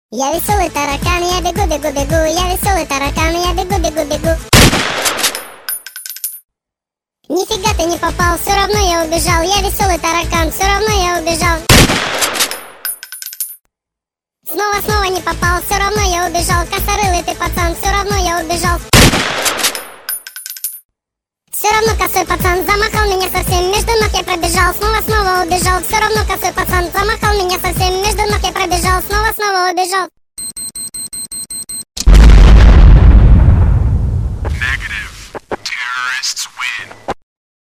прикольная песенка с прикольным концом